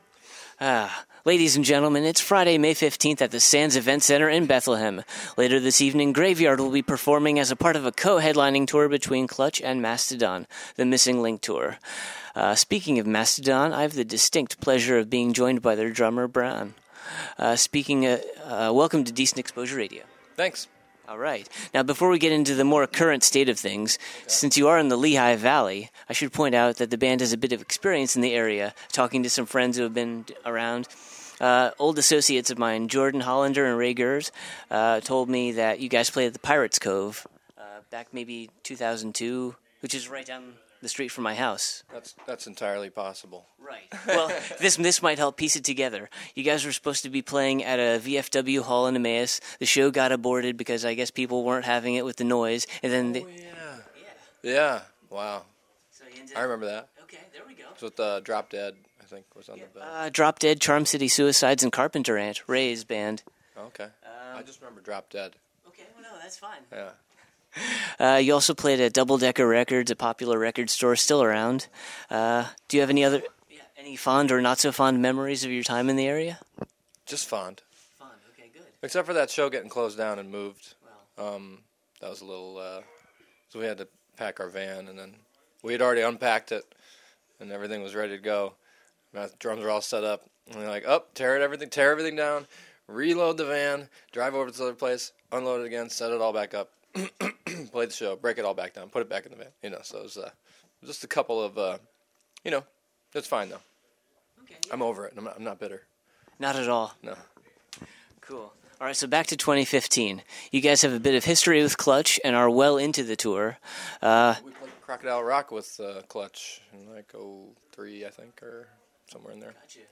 Exclusive: Mastodon Interview
58-interview-mastodon.mp3